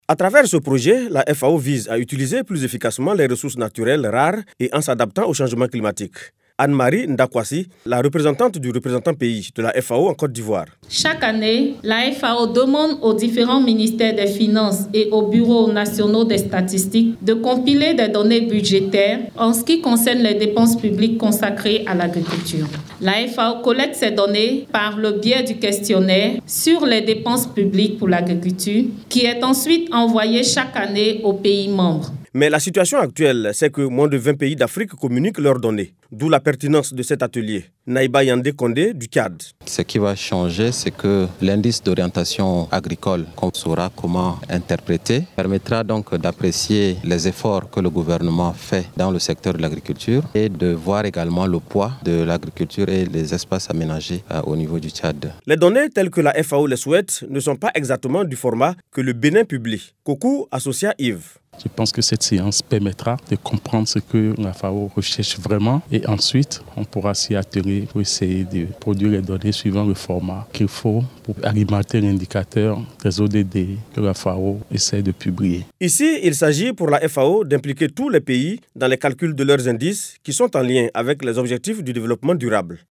Dans la perspective d’encourager les pays à transmettre leurs données, la FAO organise un atelier international de renforcement de capacité sur les statistiques de finances publiques dans l’agriculture. C’est à Grand Bassam du 04 au 06 octobre 2022.